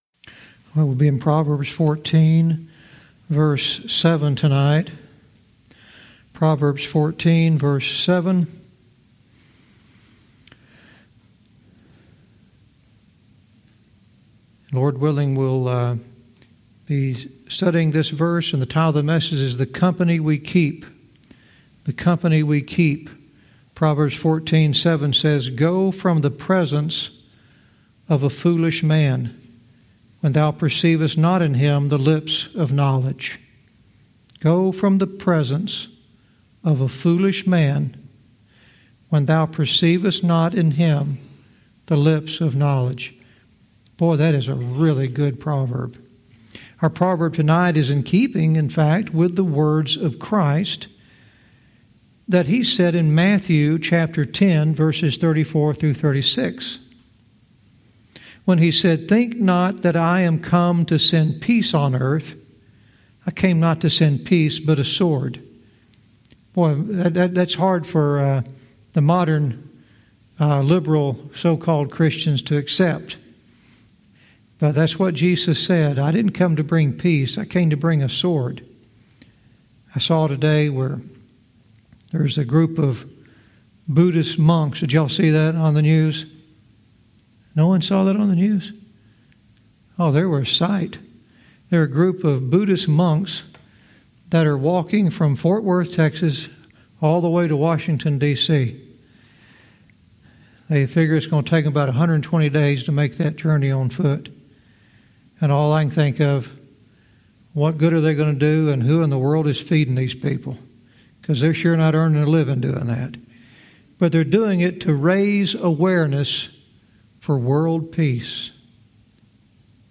Verse by verse teaching - Proverbs 14:7 "The Company We Keep"